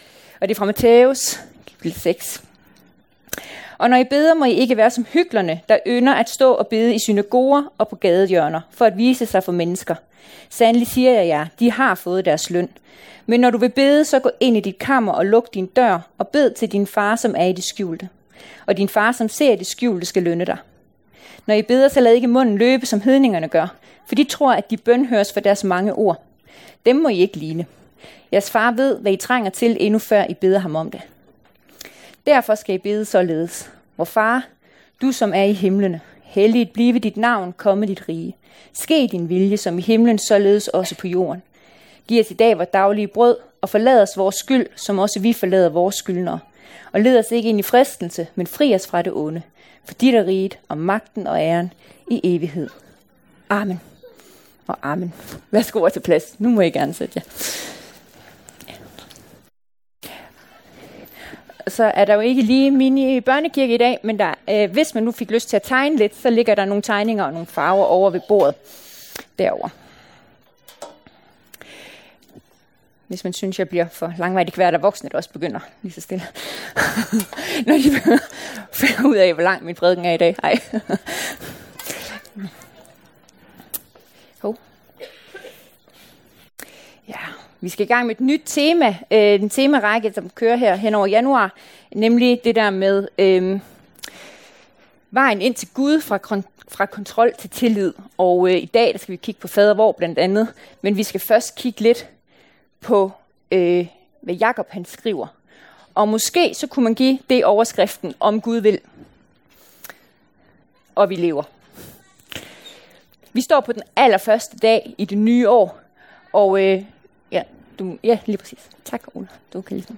Prædikener